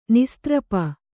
♪ nistrapa